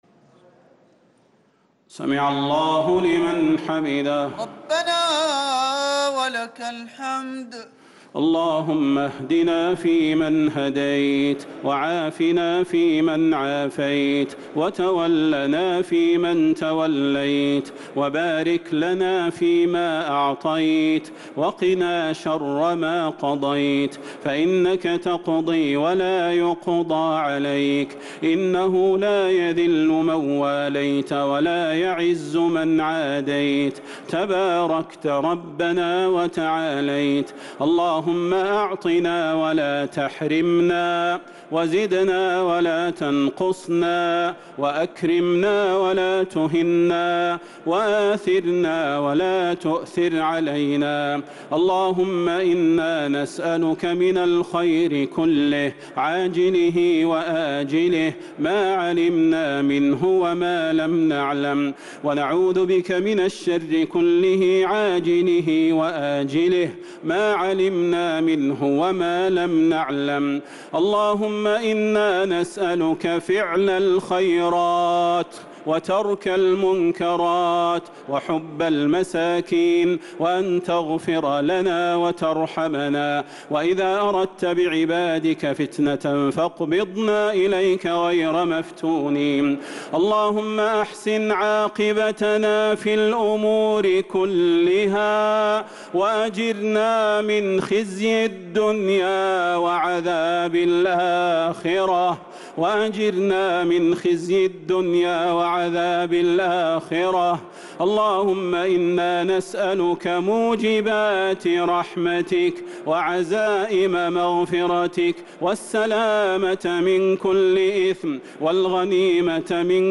دعاء القنوت ليلة 14 رمضان 1444هـ | Dua for the night of 14 Ramadan 1444H > تراويح الحرم النبوي عام 1444 🕌 > التراويح - تلاوات الحرمين